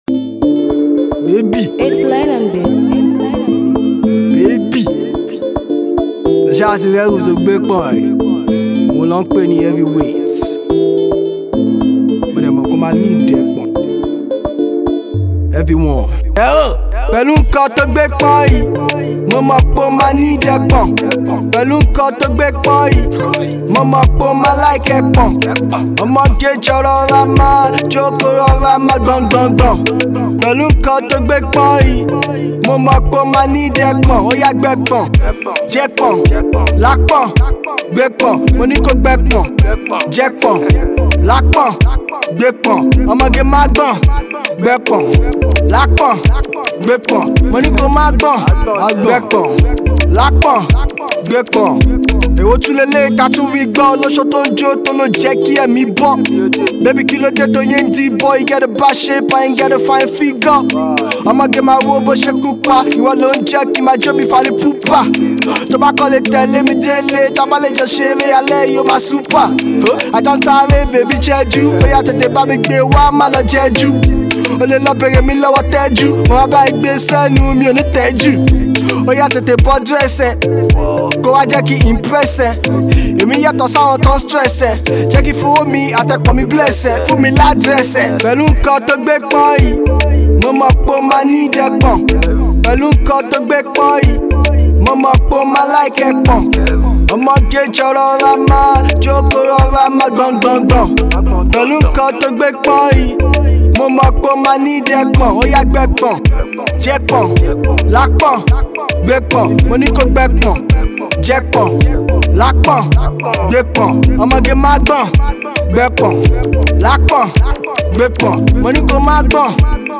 commercial rap dance tune.